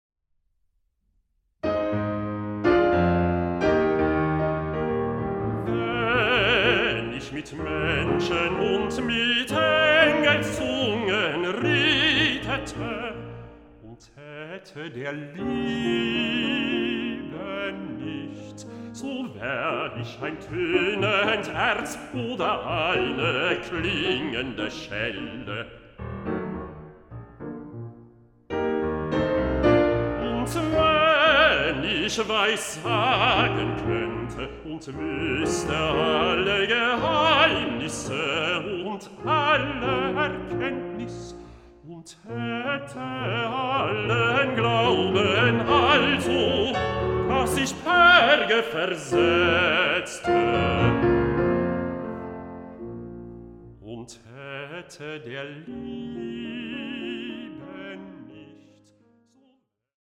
Bassbariton
Klavier
Aufnahme: Ölbergkirche Berlin, 2024